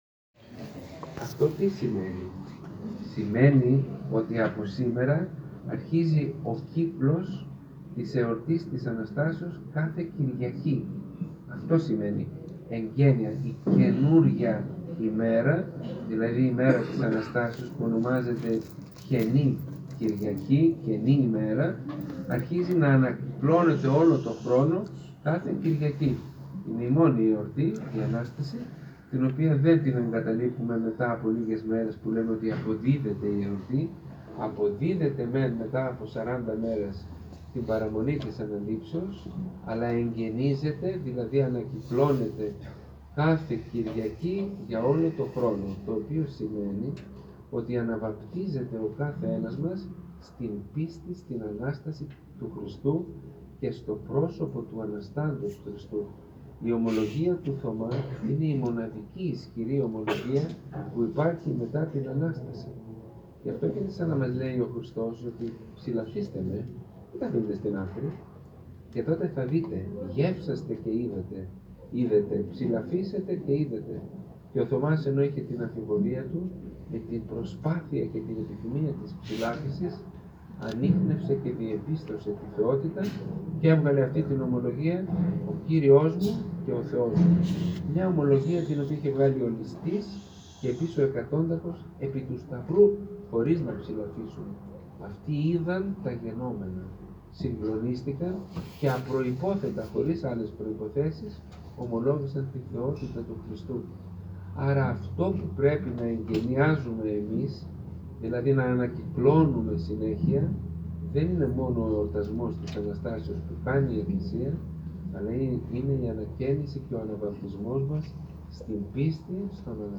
Σε κλίμα ιδιαίτερης κατάνυξης τελέσθηκε χθες το απόγευμα ο Εσπερινός, για την εορτή του Αποστόλου Θωμά, στα Σπάτα στο ομώνυμο εξωκκλήσι (σ.σ. Αγίου Θωμά) χοροστατήσαντος του Σεβασμιωτάτου Μητροπολίτου Μεσογαίας και Λαυρεωτικής κ. Νικολάου. Κατά την ομιλία του ο Σεβασμιώτατος μίλησε για την απόδοση του Πάσχα αλλά και για τις ακολουθίες μετά την Κυριακή του Θωμά, το νόημά τους και τα αναστάσιμα μηνύματα, “Αναβαπτίζεται ο καθένας μας στην πίστη, στην Ανάσταση του Χριστού και στο πρόσωπο του Αναστάντος Χριστού.
Ακούστε το χαρακτηριστικό απόσπασμα από την ομιλία του Σεβασμιωτάτου Μητροπολίτου Μεσογαίας και Λαυρεωτικής κ. Νικολάου: